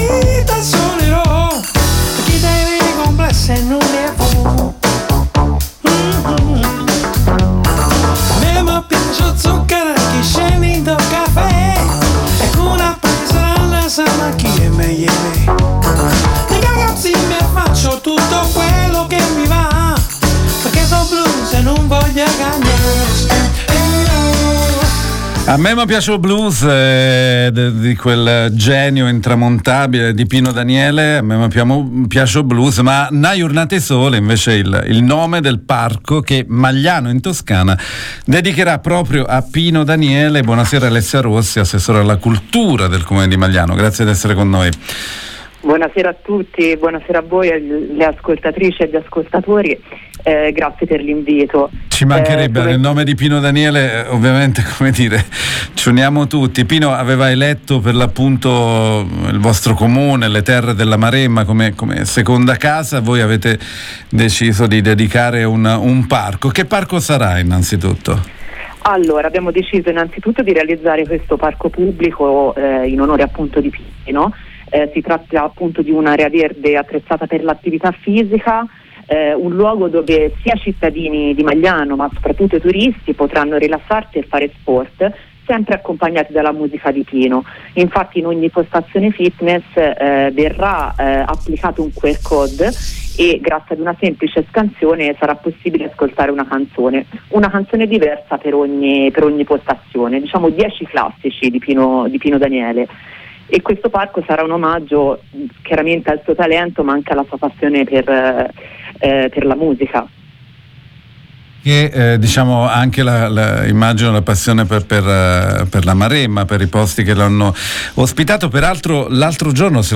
A Magliano in Maremma un parco per Pino DanieleIl musicista e compositore napoletano aveva eletto la Maremma e il comune di Magliano come buon rituro e seconda casa. Intervista con  ALESSIA ROSSI, assessora cultura comune Magliano